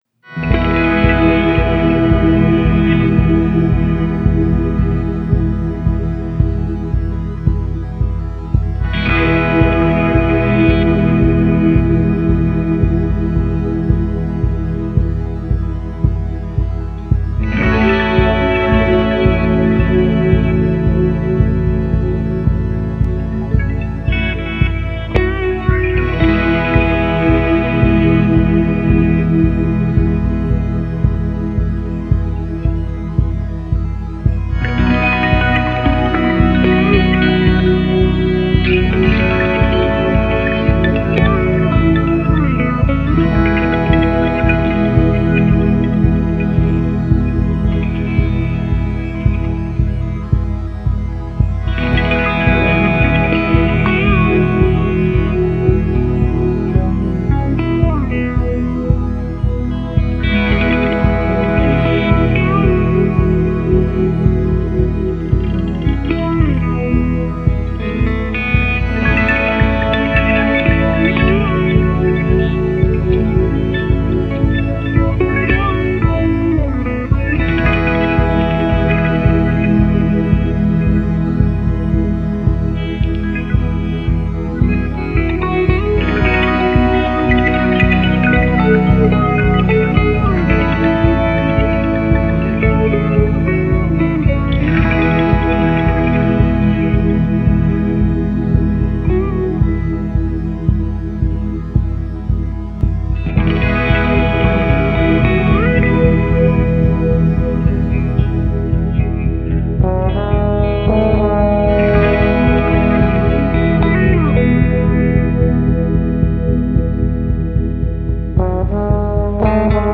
Sustained six string mutations.
Warm mellow relaxed hopeful guitar ambience with trumpet.